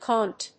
/kˈɔːnt(米国英語), kˈɔnt(英国英語)/